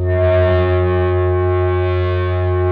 PRS FBACK 8.wav